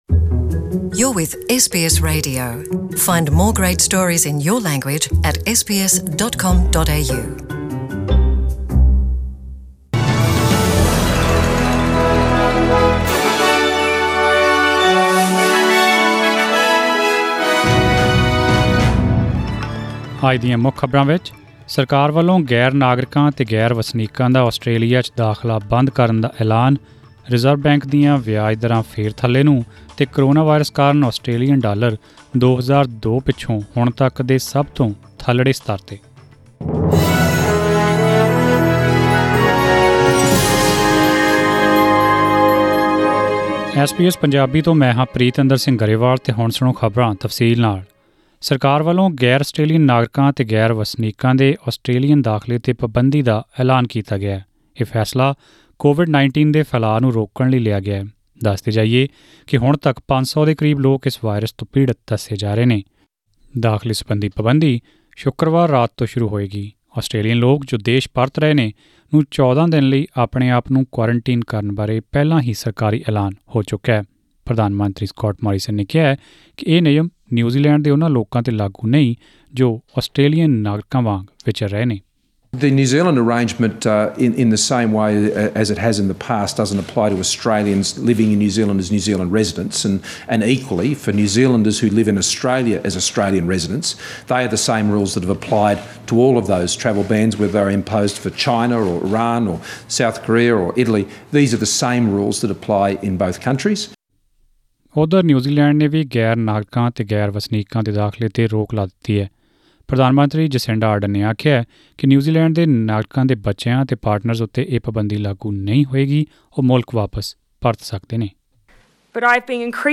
The Prime Minister announces a travel ban for non-citizens and non-residents arriving in Australia; The Reserve Bank of Australia cuts interest rates to a new record low; and in sport, the AFL begins tonight in Melbourne with Richmond playing Carlton. Listen to this and much more in today’s news bulletin.